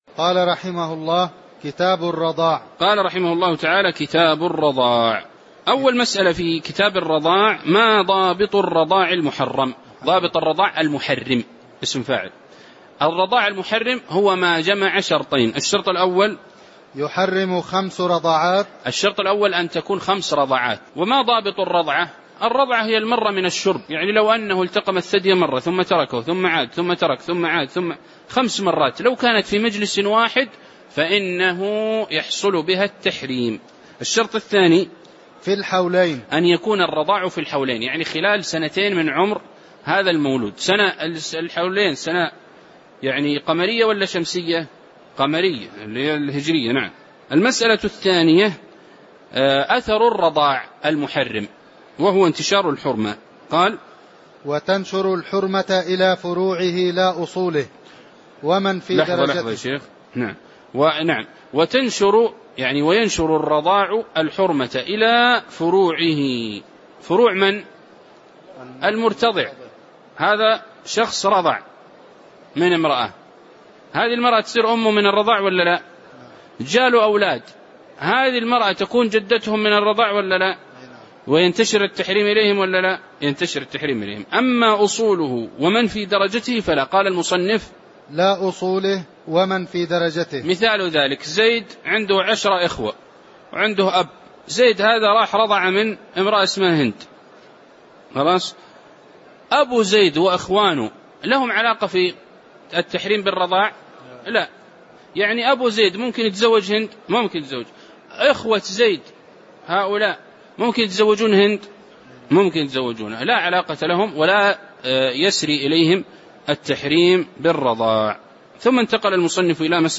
تاريخ النشر ٢١ شوال ١٤٣٩ هـ المكان: المسجد النبوي الشيخ